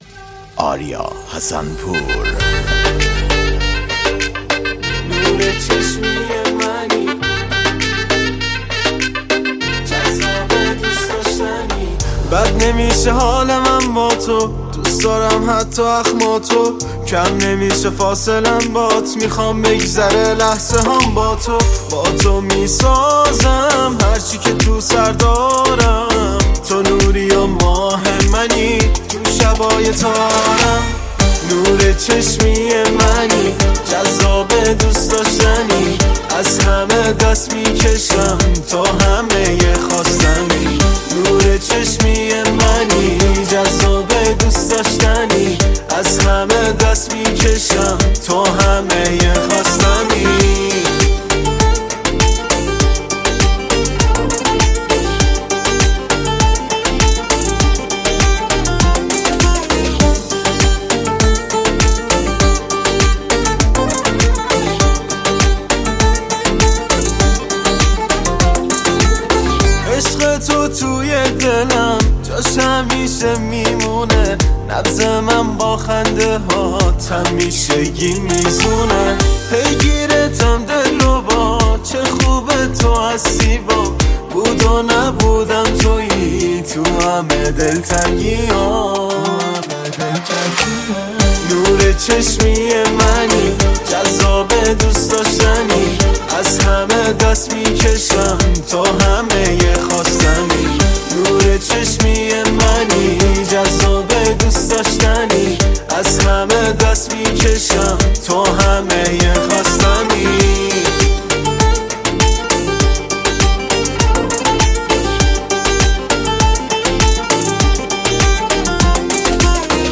موسیقی
آهنگهای پاپ فارسی